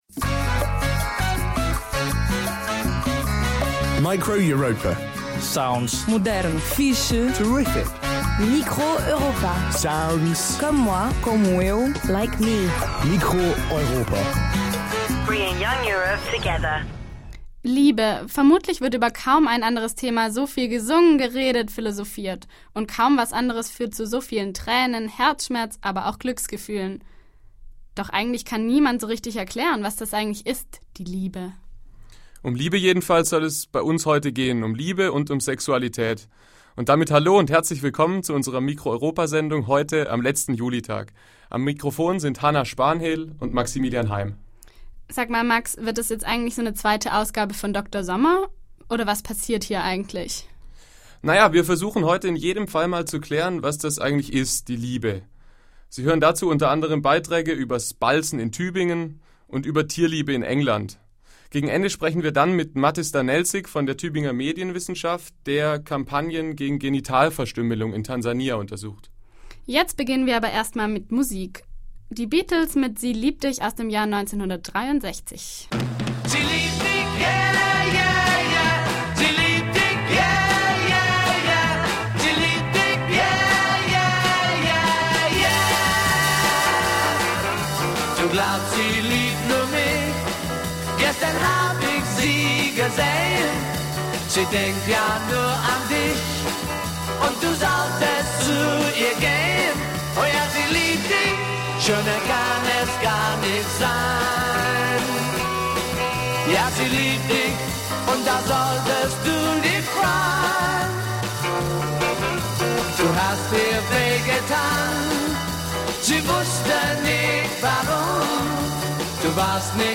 Ausschnitte vom Zimmertheaterstück "Balzen in Tübingen" - Drei Männer singen von Glücksgefühlen, Herzschmerz und Tränen. Das humorvolle Stück zeigt, dass auch Männer Herzen haben.